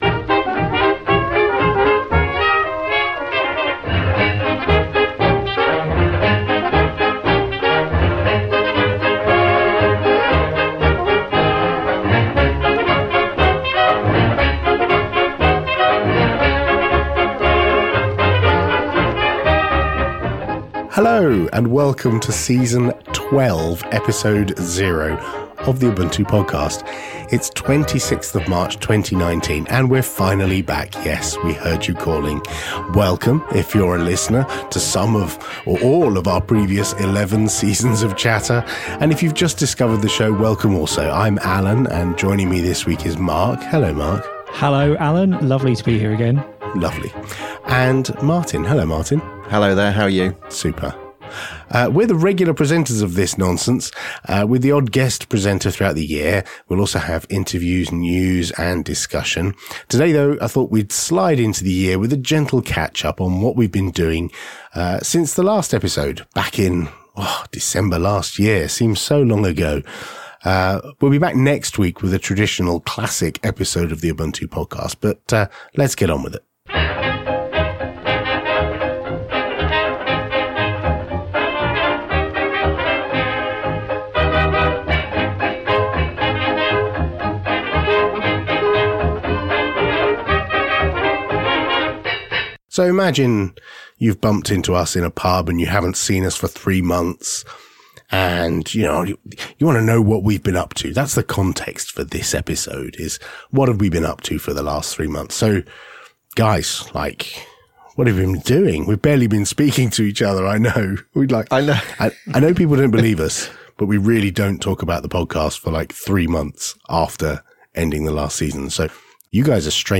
To ease ourselves into Season 12 we have a chat about what we’ve been up to since the end of Season 11.